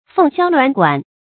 凤箫鸾管 fèng xiāo luán guǎn
凤箫鸾管发音
成语注音ㄈㄥˋ ㄒㄧㄠ ㄌㄨㄢˊ ㄍㄨㄢˇ